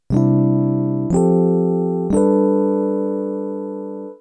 Voicing C: 1st, 5th, 2nd, 3rd
This gives the characteristic dissonant touch to the basic major sound that is typical of the Steely Dan type I mu major.
G mu major
Listen Sound Examples: listen to the type I mu major, voicing C